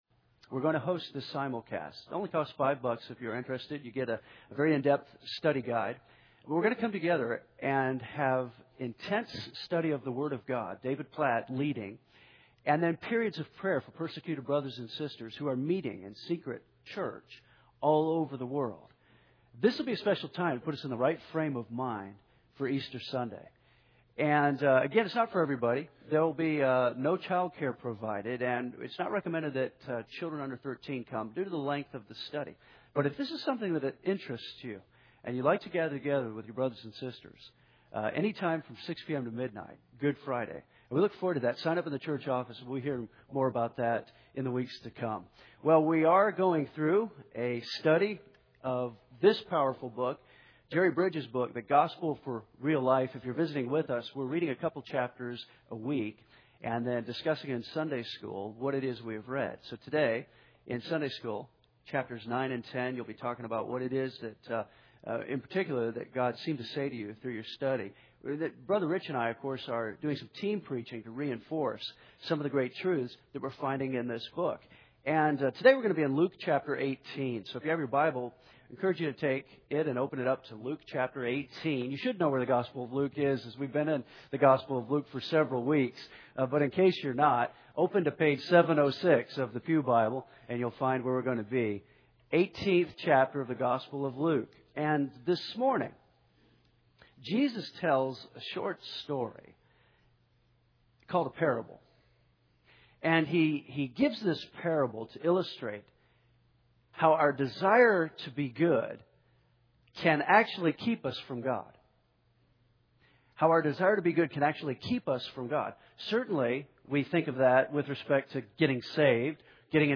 Team Preaching